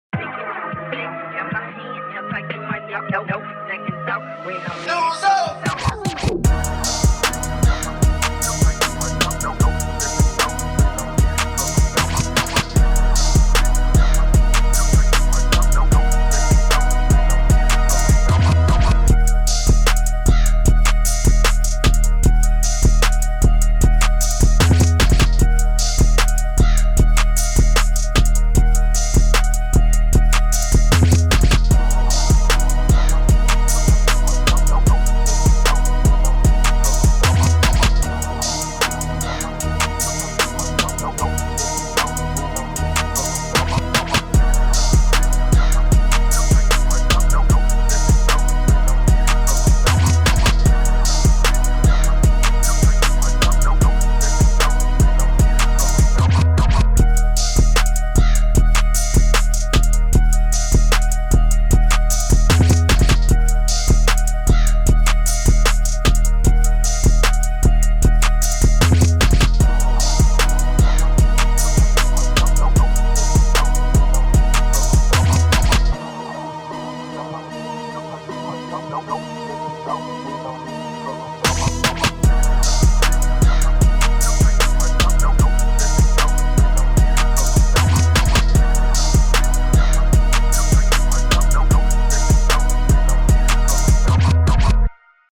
official instrumental
Rap Instrumentals